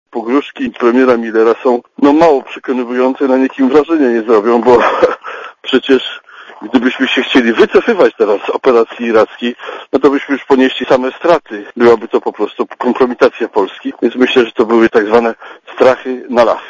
Mówi Bronisław Komorowski (69 KB)